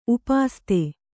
♪ upāste